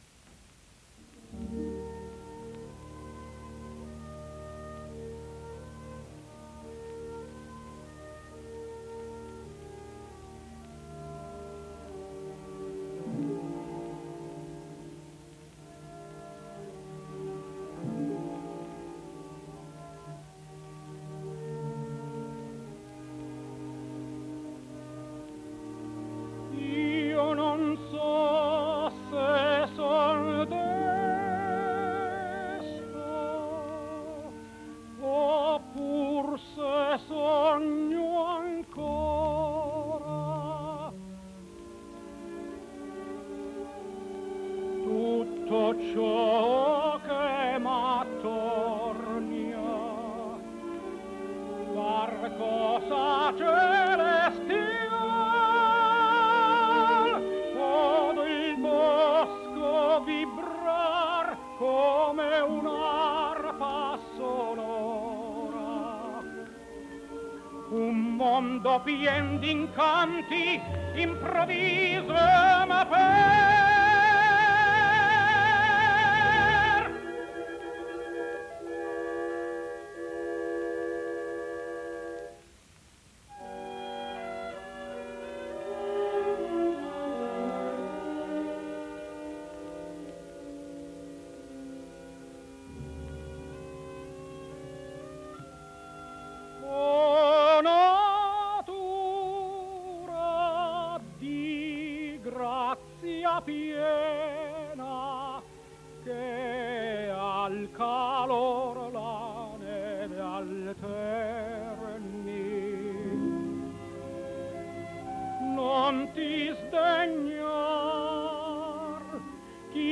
Tenore TITO SCHIPA “L’emozione”